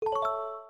I put a little music theory into the chimes, and came up with a high-pitch rolled major triad on the glockenspiel for the 'Yes' chime.
The 'Yes' chime is in A major, and the 'No' plays an F#, which is the tonic of the relative minor.
Audio for the 'Yes' chime